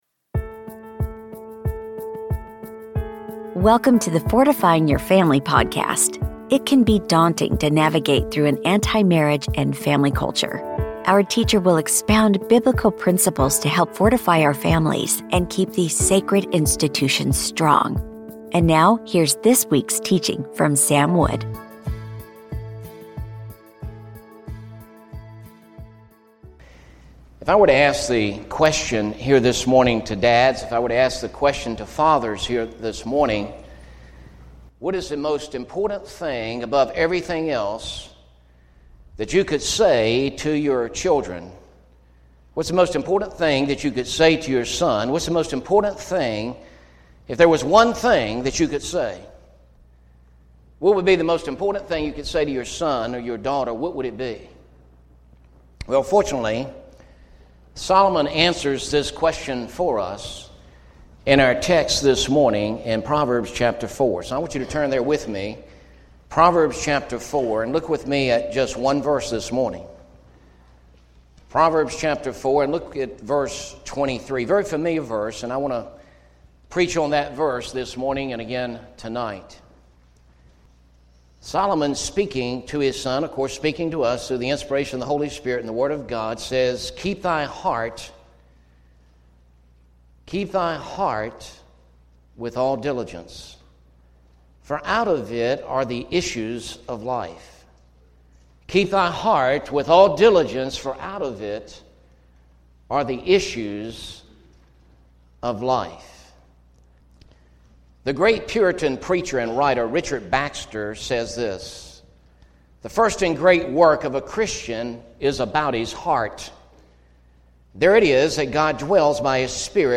As we launch into a new year, the teaching from Proverbs 4:23 serves as a powerful reminder that the condition of our hearts should be our utmost priority. The podcast message resonates with urgency, calling for a renewed commitment to spiritual integrity, illustrating how a guarded heart leads to a flourishing family life and reflects the love of Christ in action.